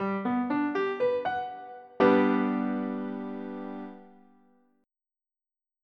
MIDI Music File
_GMAJ7.mp3